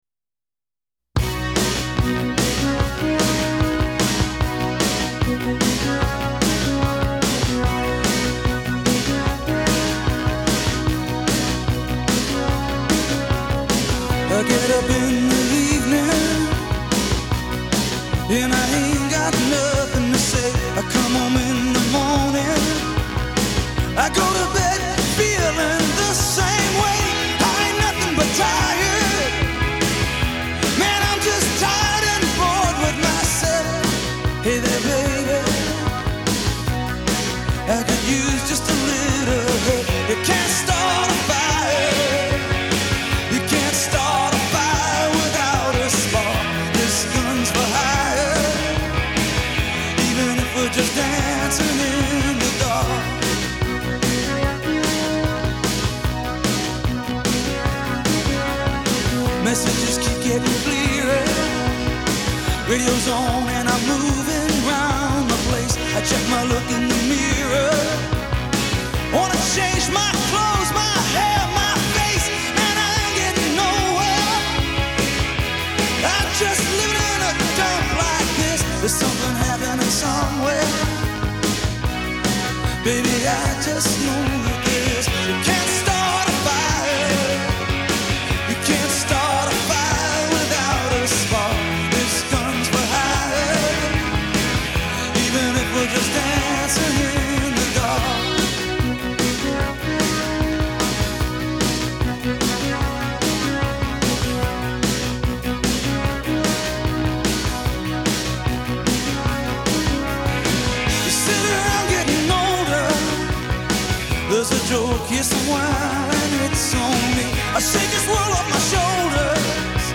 and its simple drum beat makes you want to move.